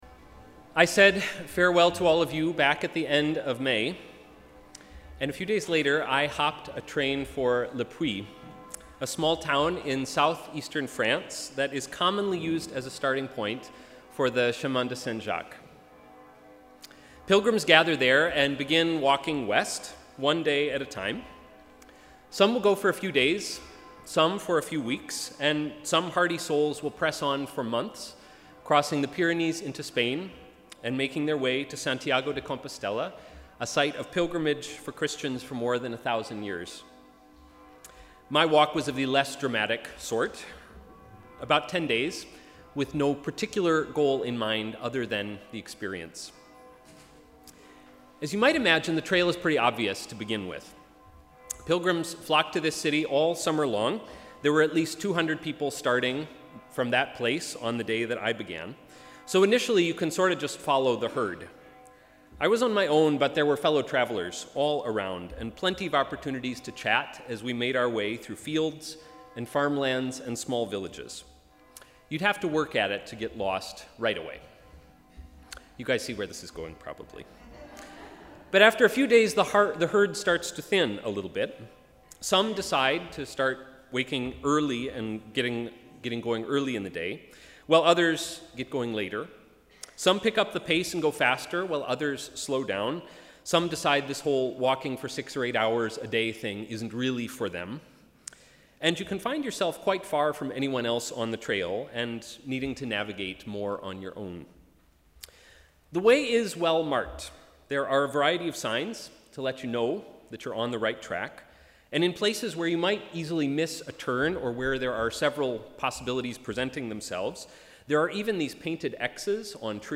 Sermon: ‘Finding the trail again’